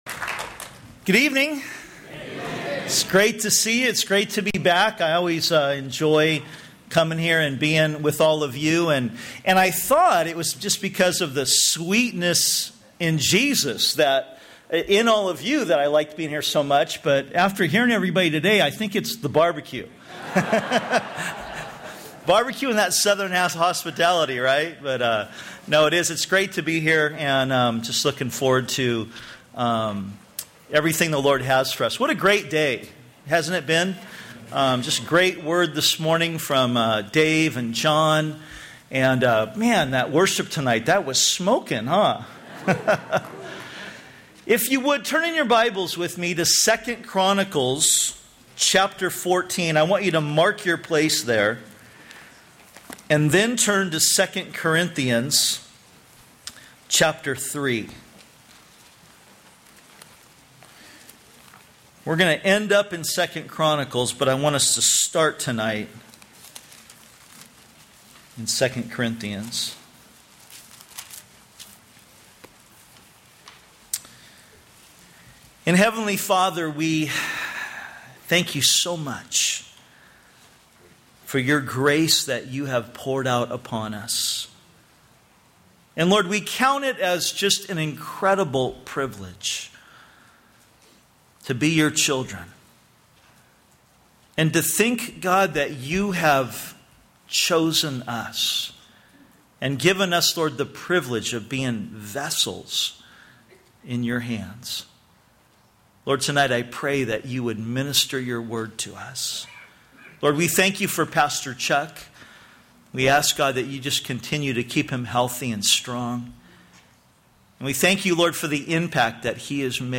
2010 Home » Sermons » Session 3 Share Facebook Twitter LinkedIn Email Topics